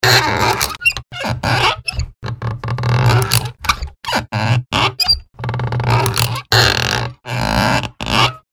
Gemafreie Sounds: Handwerk
mf_SE-6334-rubber_metall_squeak_seq.mp3